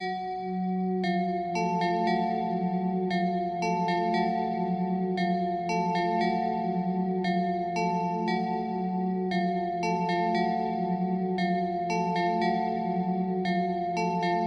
黑暗陷阱钢琴介绍
Tag: 116 bpm Hip Hop Loops Piano Loops 2.44 MB wav Key : C